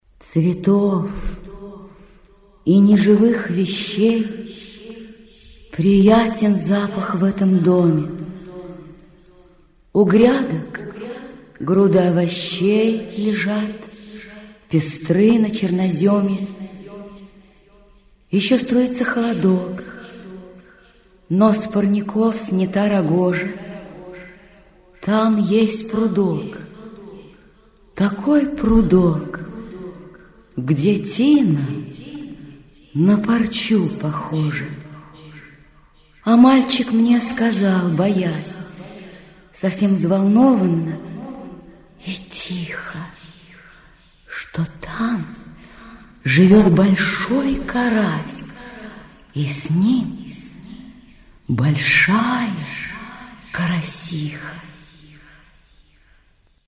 4. «стихи Анна Ахматова читает НИНА ДРОБЫШЕВА – Цветов и неживых вещей» /